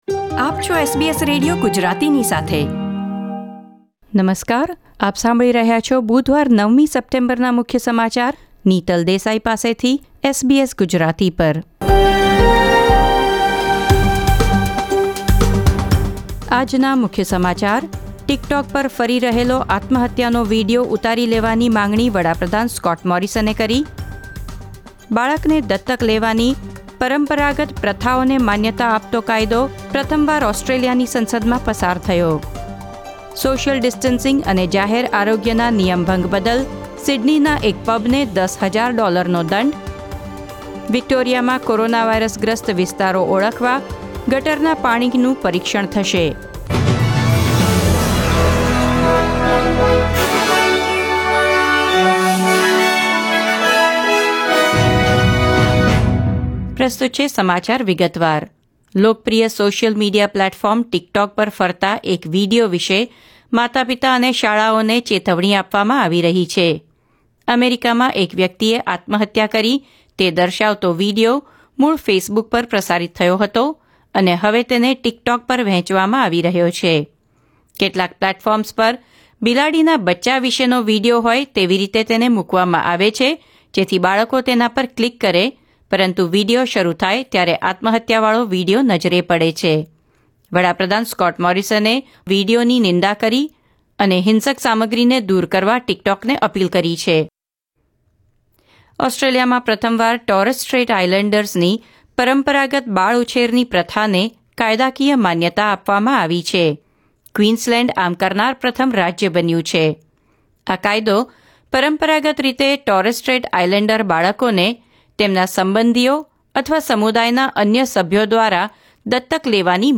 SBS Gujarati News Bulletin 9 September 2020